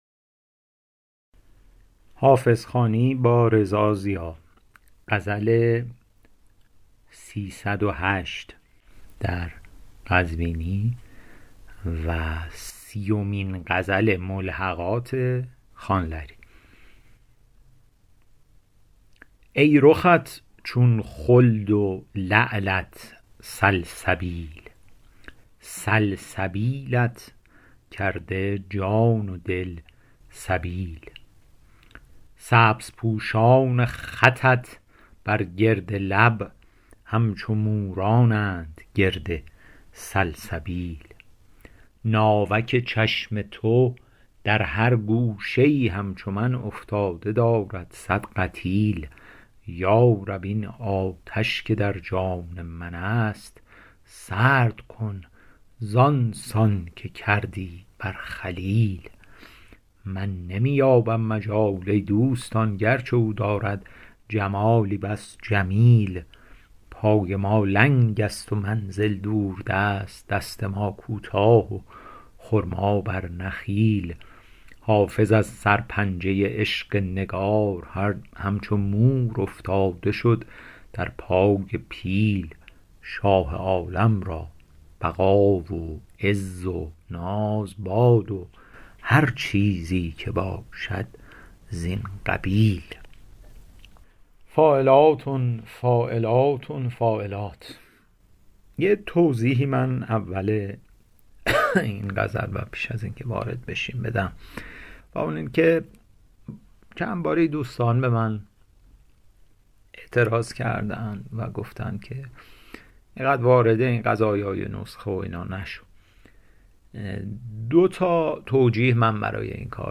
شرح صوتی غزل شمارهٔ ۳۰۸